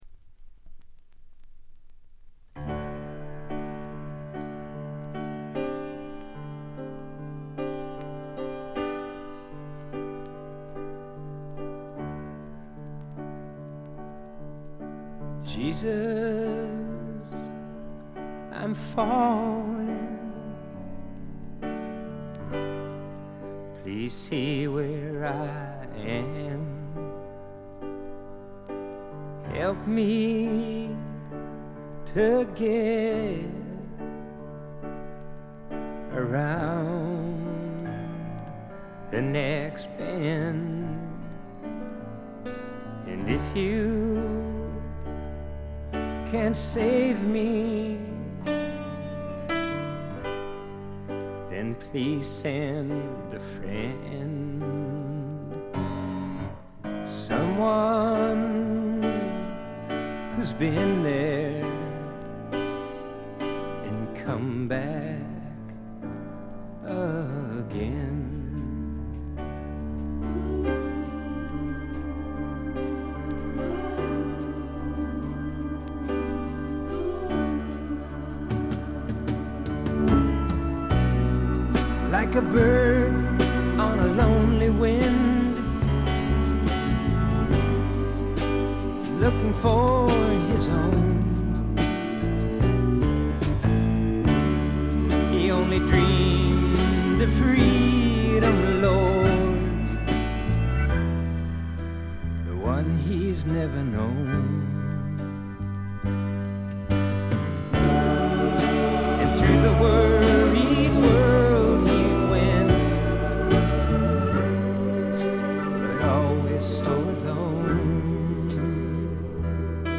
部分試聴ですが、軽いチリプチ、散発的なプツ音少し。
控えめな演奏をバックに複雑な心象風景を淡々と描いていく歌声が本作の最大の魅力でしょう。
試聴曲は現品からの取り込み音源です。